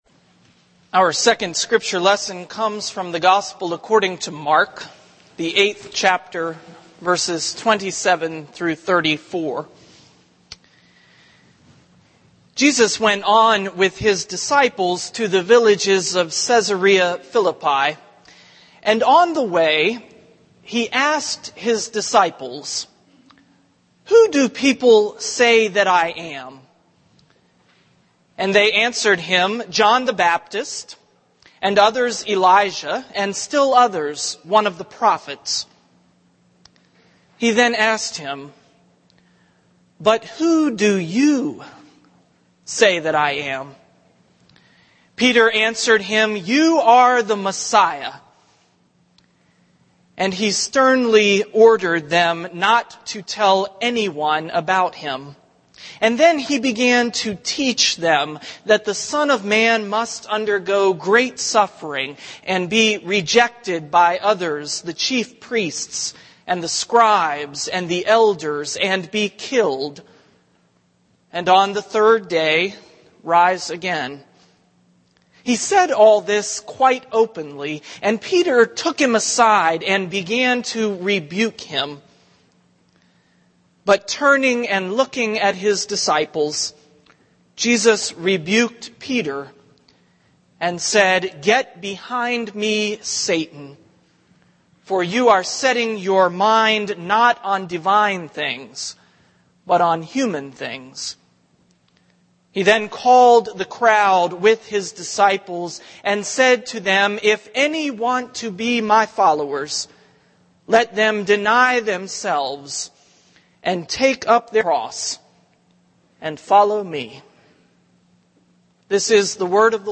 THE SERMON Burning Questions for 21st Century Christians